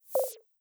Modern UI SFX / SlidesAndTransitions
Minimize3.wav